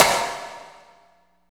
48.01 SNR.wav